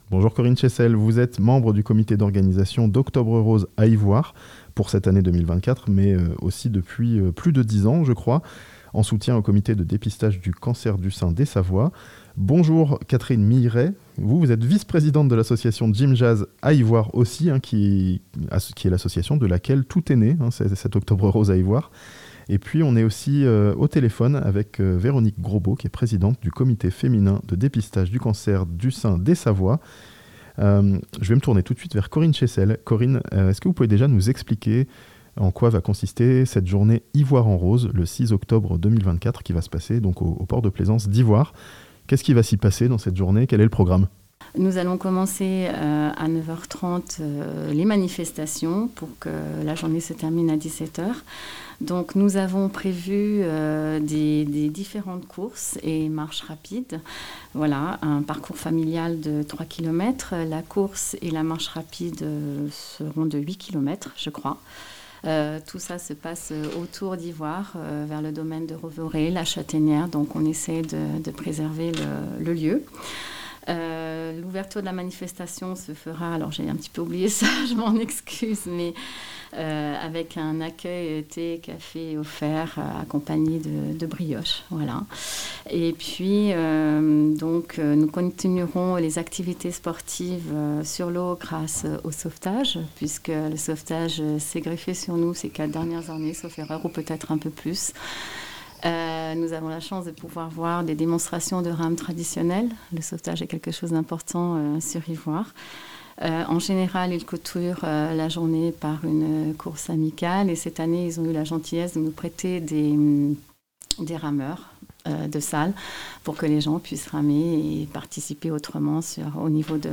Yvoire se mobilise pour Octobre Rose ce dimanche (interview)